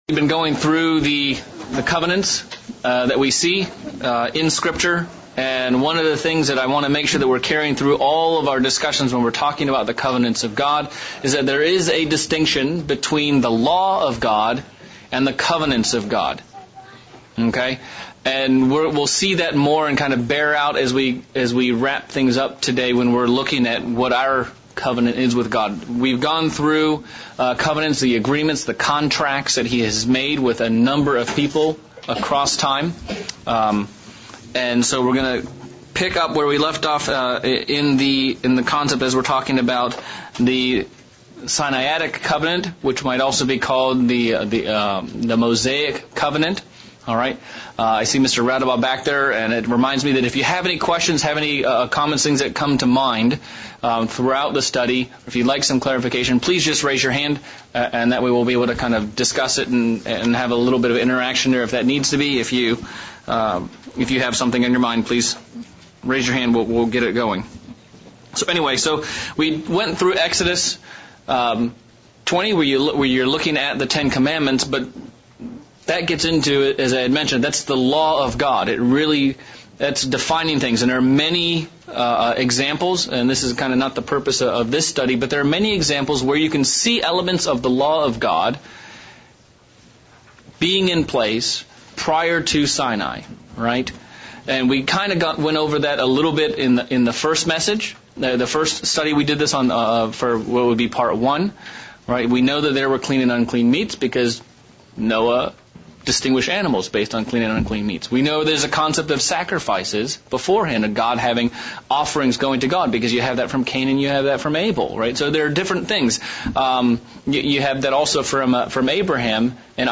January 2022 Bible Study- Covenants PT 3
Bible Study series part 3 on the Covenants. Looking at the Abrahamic covenant and what God did with and thru him as well as what was and was not part of the Sinaitic covenant.